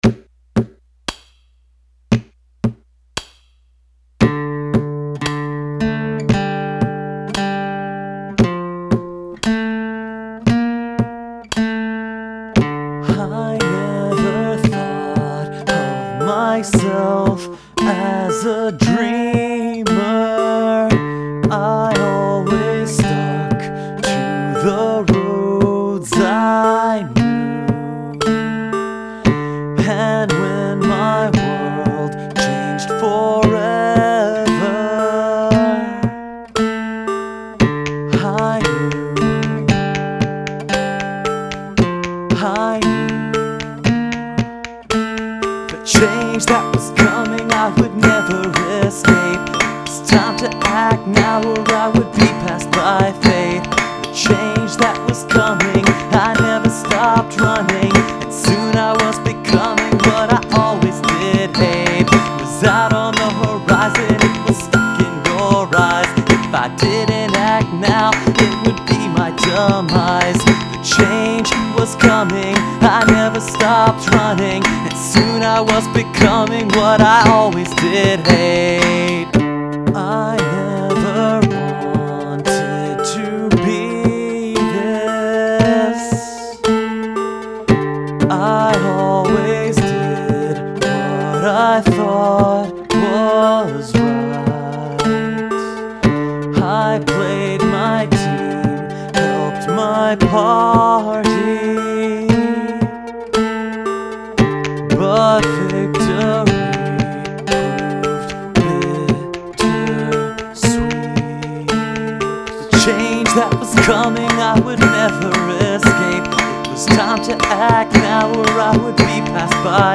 I built a new drum kit just for this compo, and I busted out my glockenspiel just for you guys.